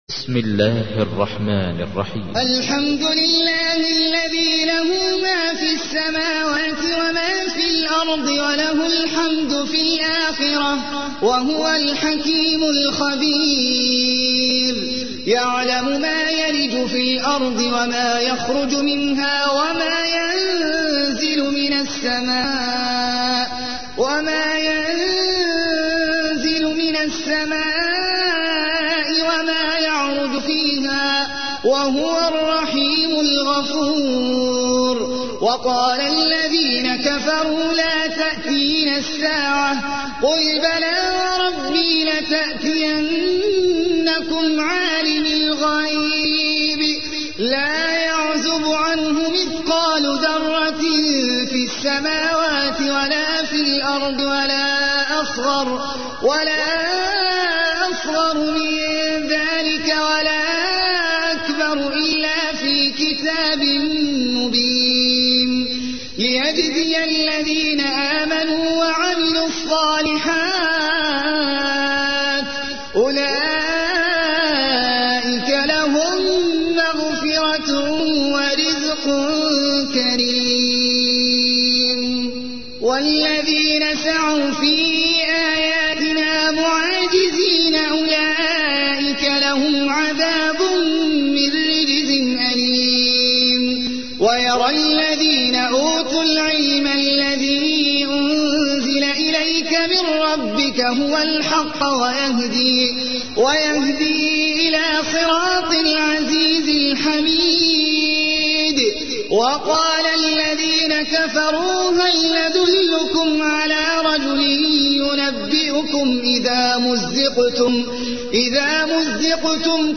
تحميل : 34. سورة سبأ / القارئ احمد العجمي / القرآن الكريم / موقع يا حسين